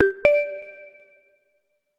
Usé tu respuesta para probar qué tal quedaba un sonido de notificación que creé en reaper. LOL
es muy simple, es esto que adjunto.
es un poco más invasivo no?
Igualmente creo que es por el volumen que le puse.
satura mucho, sí
notify1.mp3